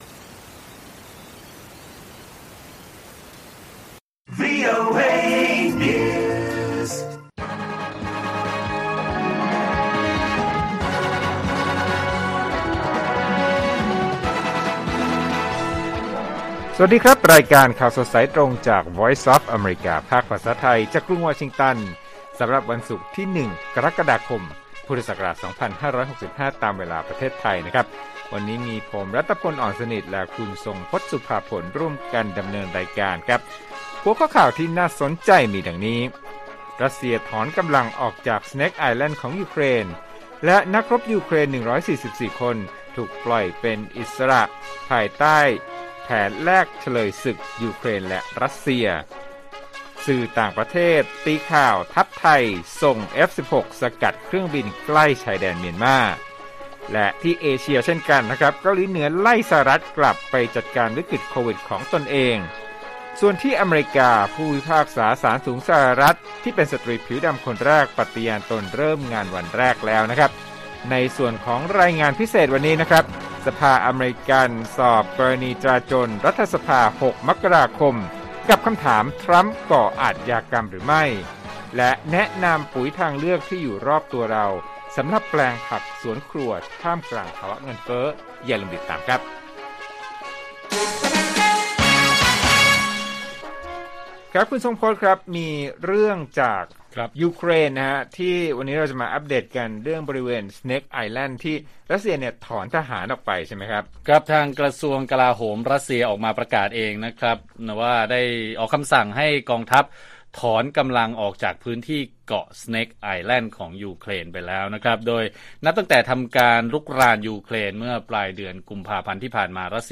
ข่าวสดสายตรงจากวีโอเอไทย 6:30 – 7:00 น. วันที่ 1 ก.ค. 2565